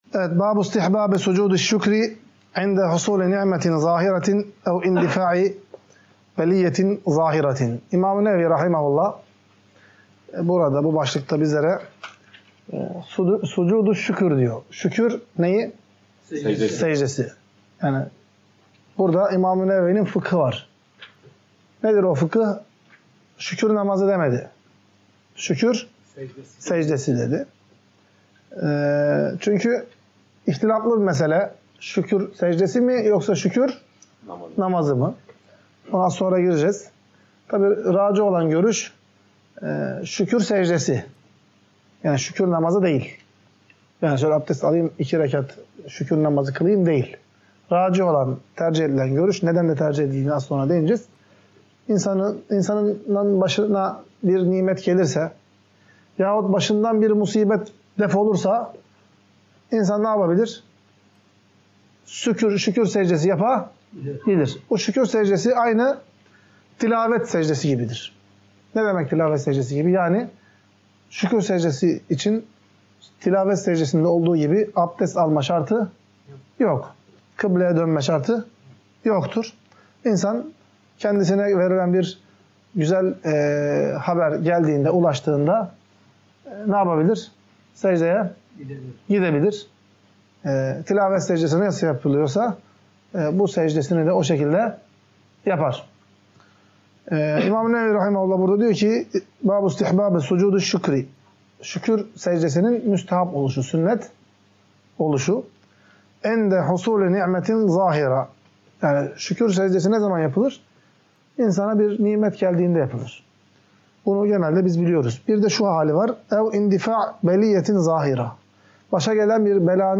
Ders - 32. BÖLÜM | BİR NİMETE KAVUŞUNCA YA DA BİR MUSİBETTEN KURTULUNCA ŞÜKÜR SECDESİ YAPMANIN MÜSTEHAB OLDUĞU